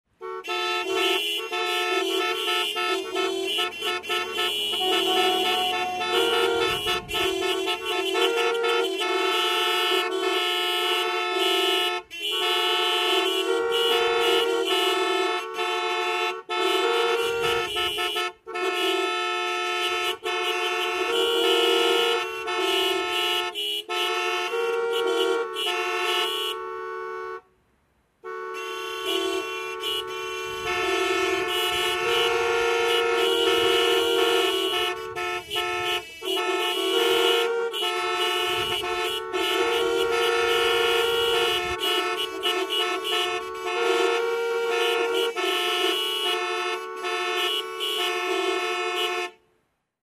Звуки автомобильных пробок
На этой странице собраны звуки автомобильных пробок — гудки машин, шум двигателей, переговоры водителей.